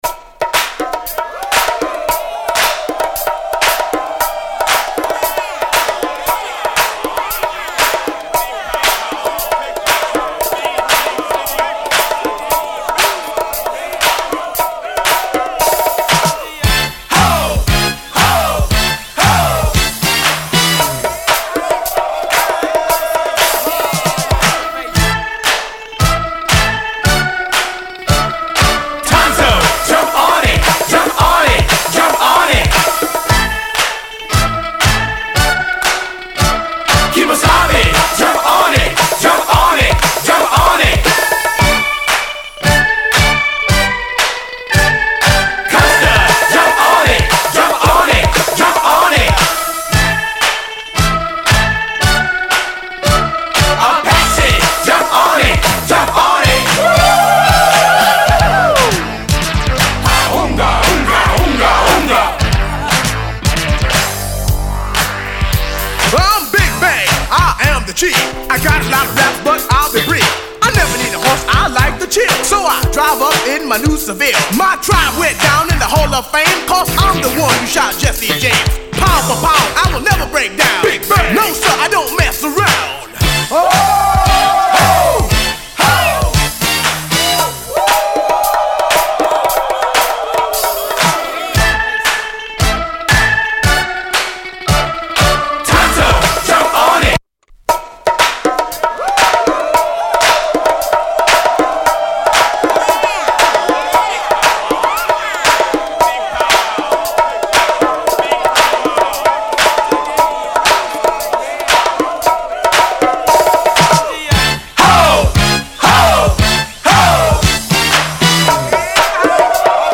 plays great
+ Instrumental